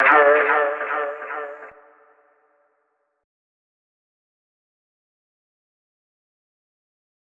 DMV3_Vox 9.wav